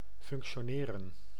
Ääntäminen
IPA: /fʊŋktsjoˈniːʁən/